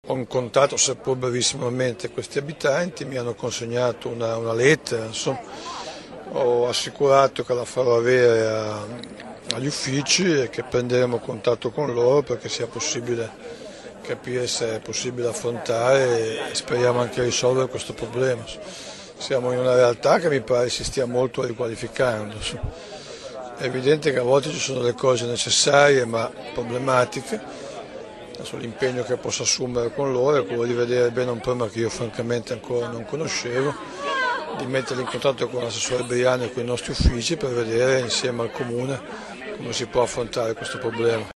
Il Presidente Burlando, che ha ammesso di non conoscere perfettamente il problema, ha comunque promesso un interessamento (l'audio dell'intervista cliccando
Burlando_su_Cava_6Nov2010.mp3